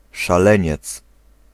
Ääntäminen
Ääntäminen France: IPA: [fu]